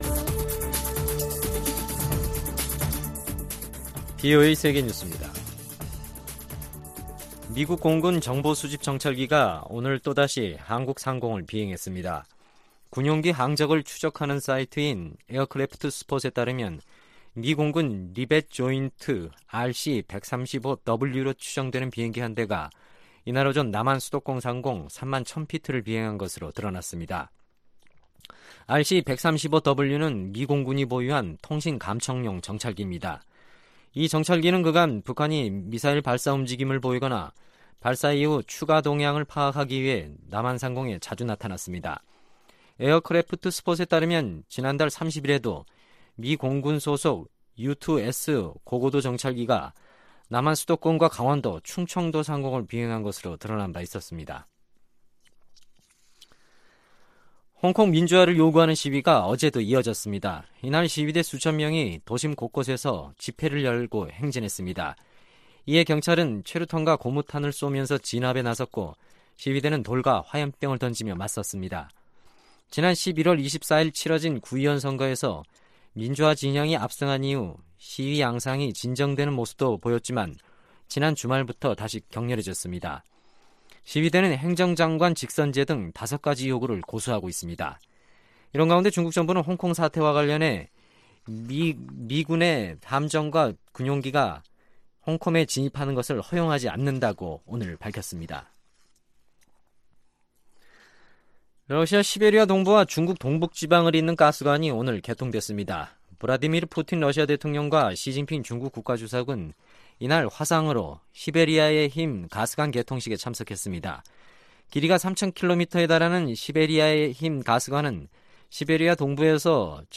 VOA 한국어 간판 뉴스 프로그램 '뉴스 투데이', 2019년 12월 2일 3부 방송입니다. 최근 미군 정찰기의 한반도 상공비행이 잇따르고 있습니다.